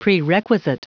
Prononciation du mot prerequisite en anglais (fichier audio)
prerequisite.wav